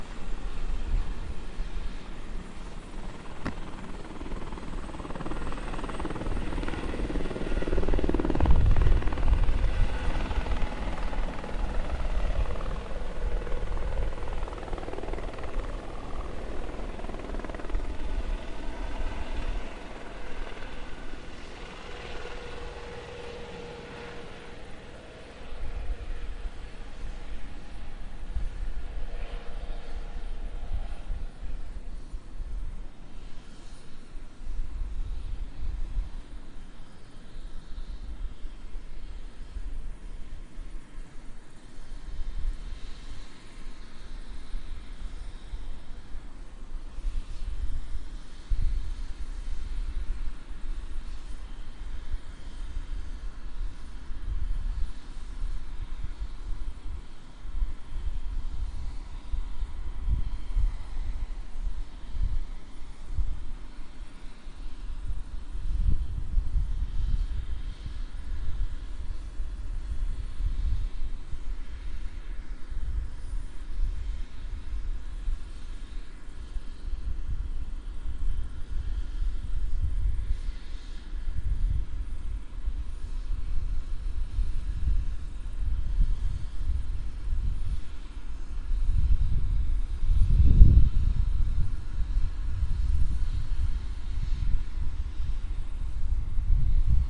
音频 I " 01 直升机
描述：Sonido de un helicoptero。
标签： 更快 Helicoptero 直升机